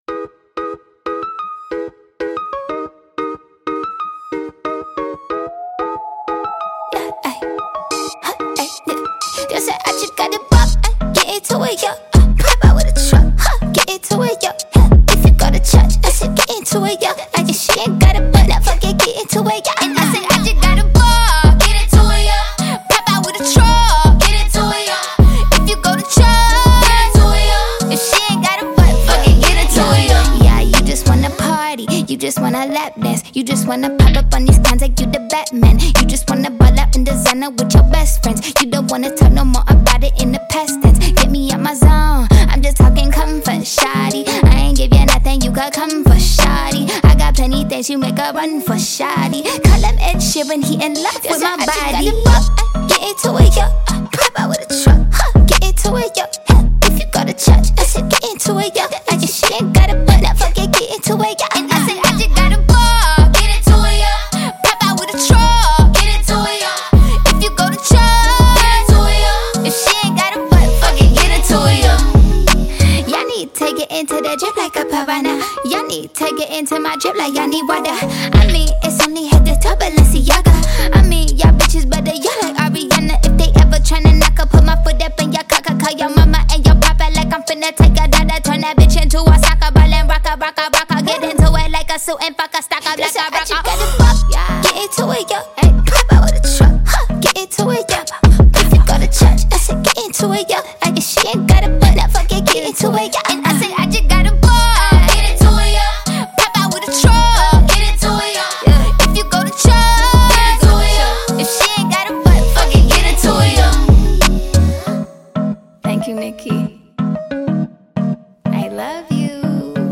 سبک اثر : پاپ , R&B و هیپ هاپ